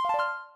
Add a bunch more sound effects
get-bonus.ogg